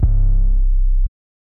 nw808.wav